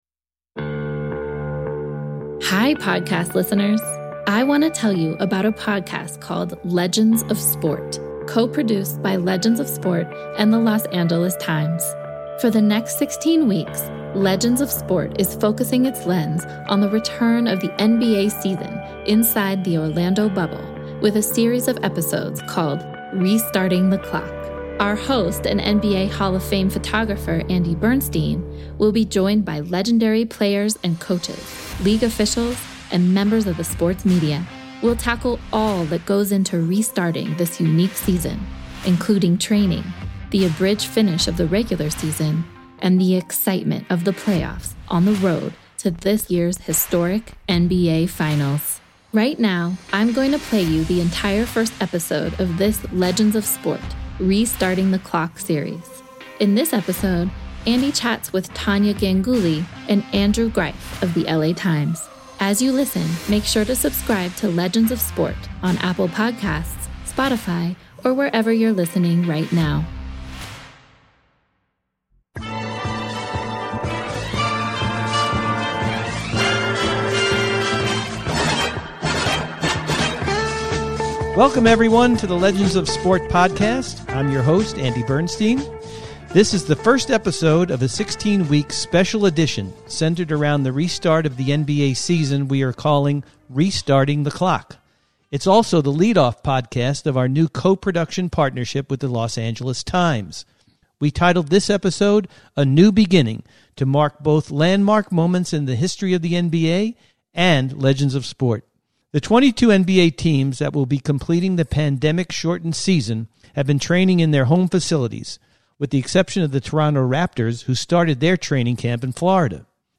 We'll provide in-depth interviews with the athletes, coaches, and front office personnel that drive our daily sports conversation.